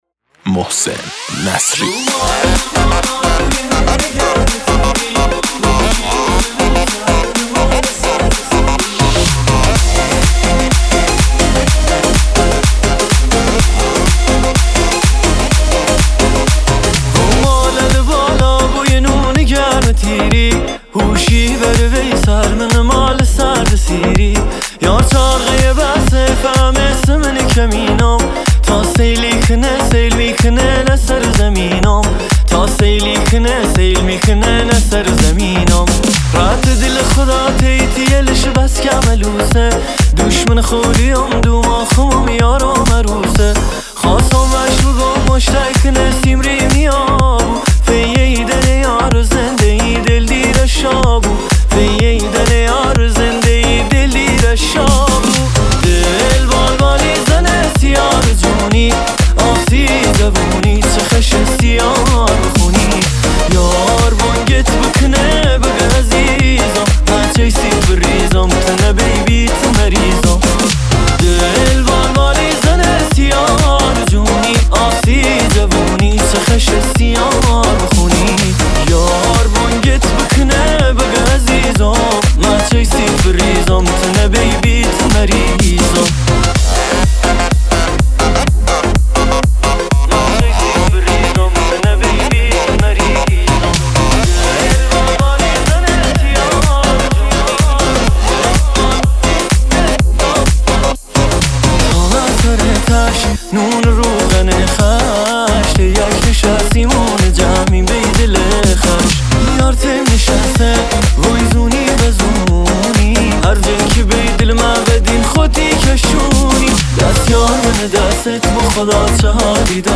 ریمیکس لری شاد